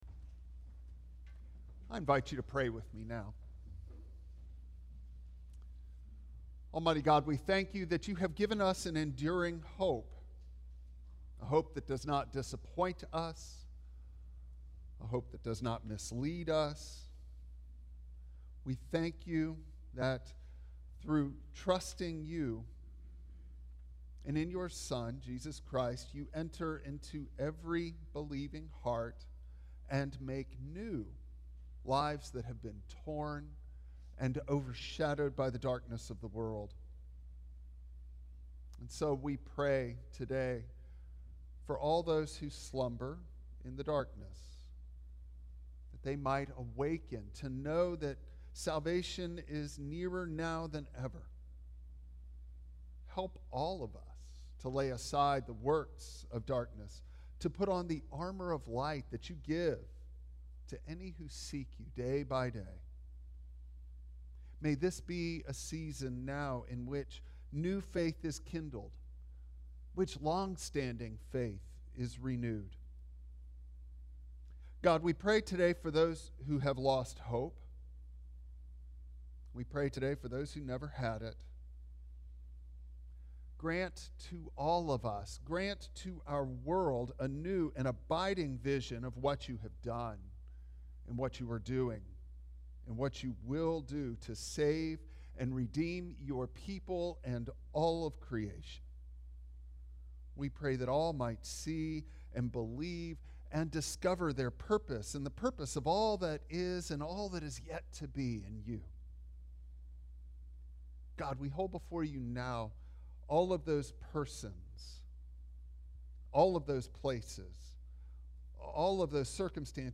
Luke 1:26-38 Service Type: Traditional Service Bible Text